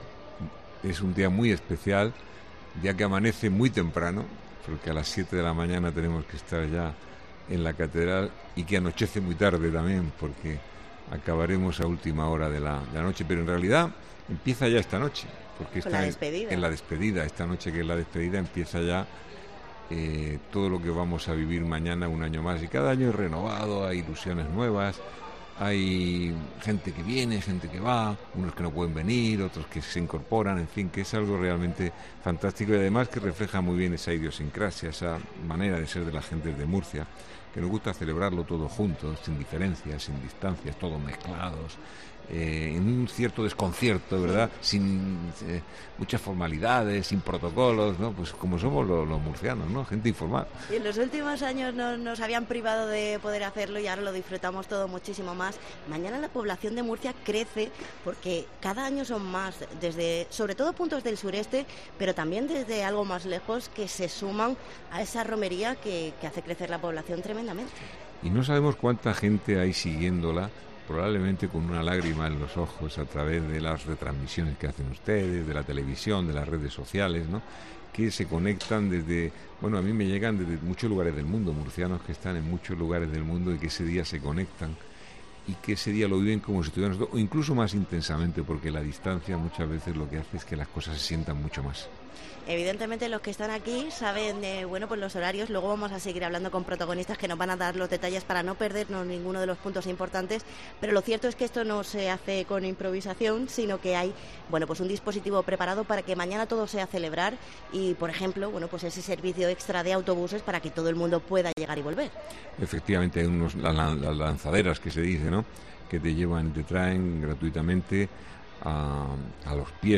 José Ballesta, alcalde de Murcia, visita el set de COPE en el programa especial Romería